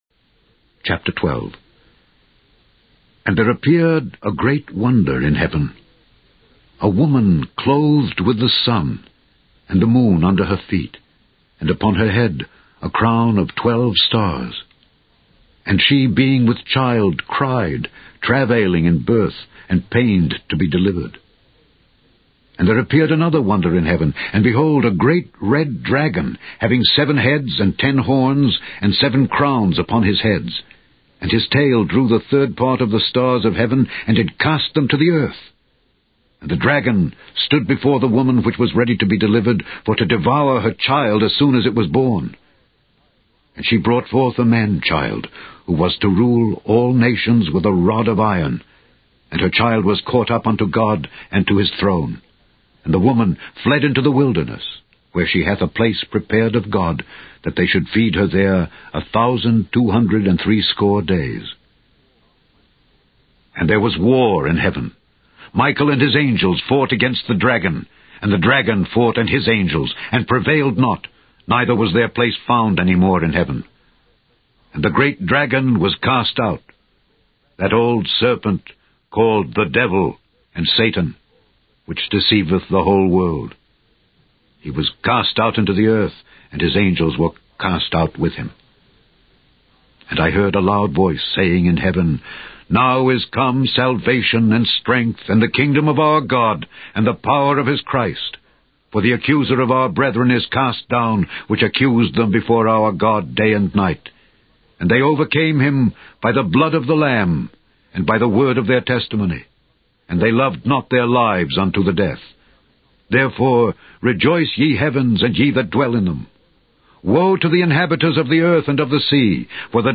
The Scourby Bible Readings are being aired with the permission of copyright owner Litchfield Associates.